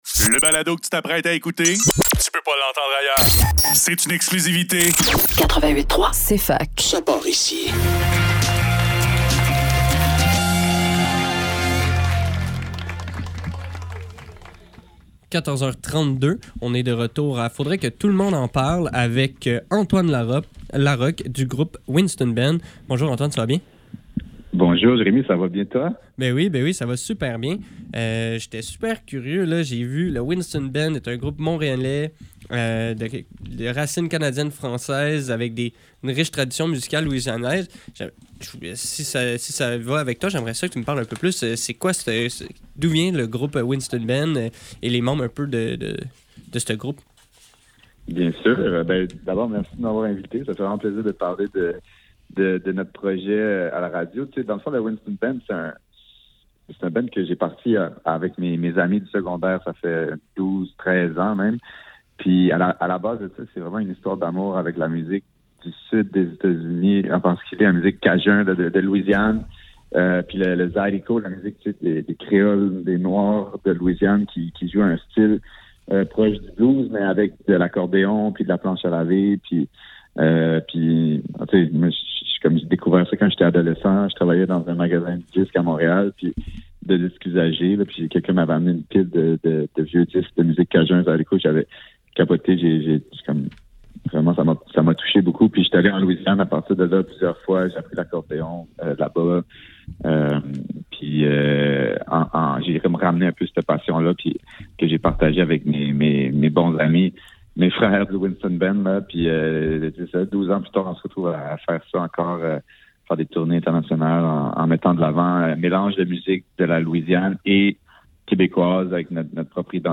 Faudrait que tout l'monde en parle - Entrevue avec Winston Band - 21 janvier 2025